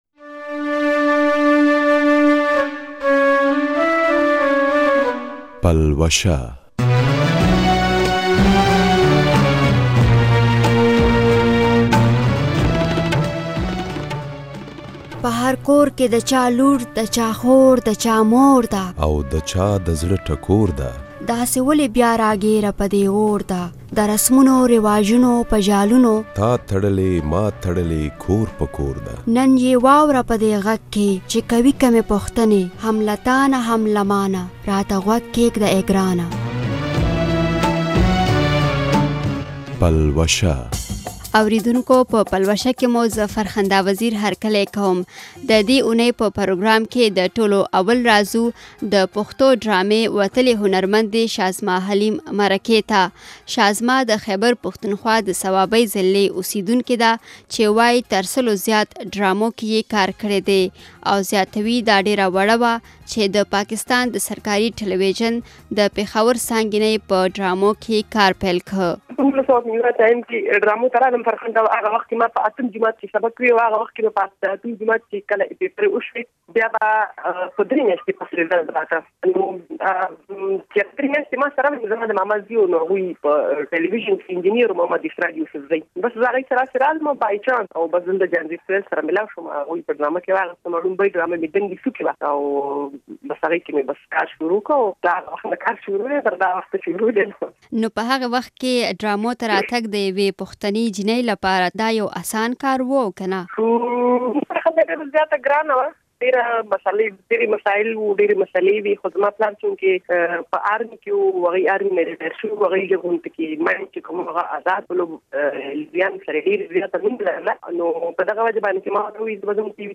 مرکه
ددی سره سره یوشمیر نور رپوټونه هم د پلوشی برخه ده.